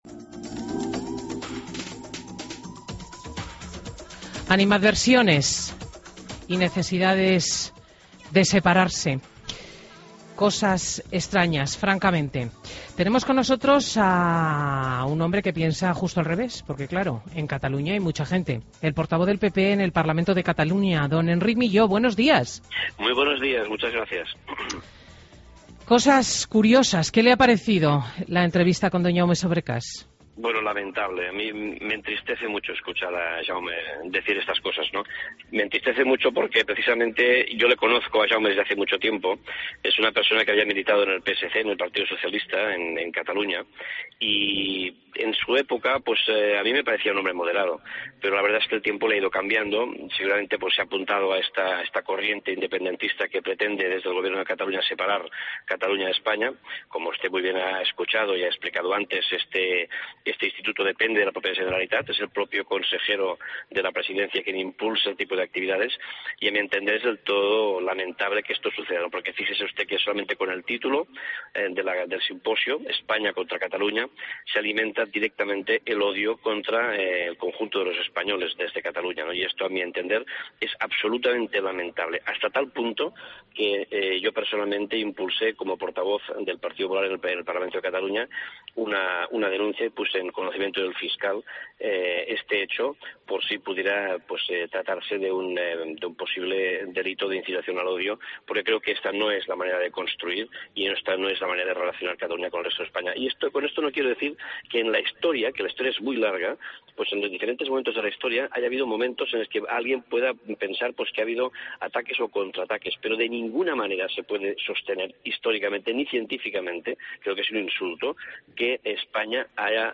Entrevista a Enric Milló en Fin de Semana COPE